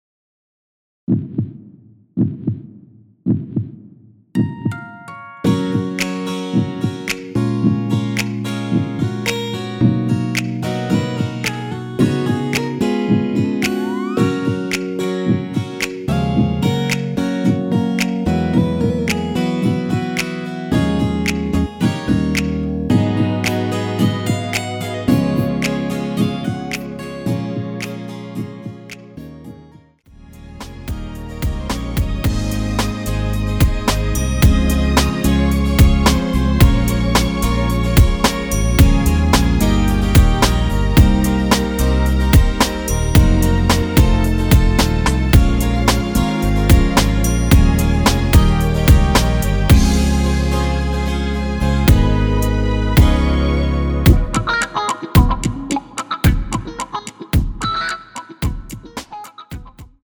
원키에서(+5)올린 MR입니다.
F#
앞부분30초, 뒷부분30초씩 편집해서 올려 드리고 있습니다.
중간에 음이 끈어지고 다시 나오는 이유는